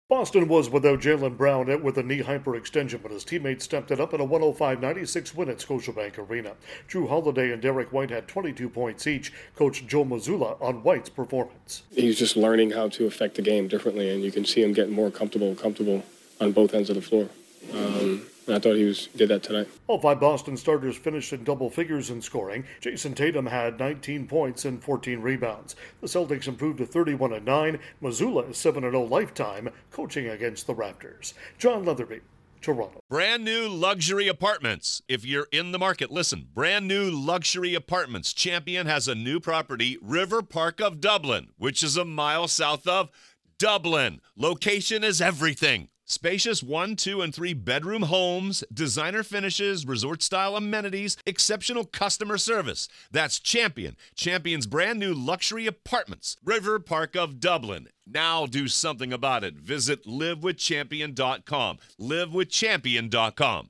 The Celtics get a victory in Canada. Correspondent